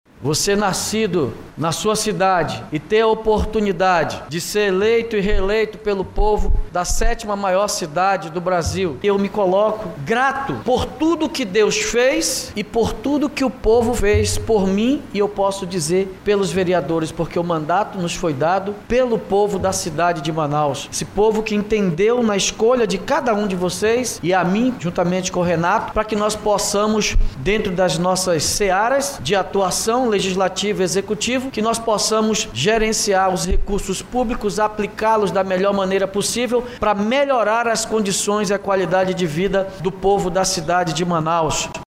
A cerimônia ocorreu no auditório do Tribunal de Justiça do Amazonas – TJAM, localizado na zona Centro-Sul da capital amazonense.
Em seu discurso, Davi Almeida, disse que ao povo pertence os mandatos dos eleitos e que devem buscar administrar a cidade da melhor maneira possível.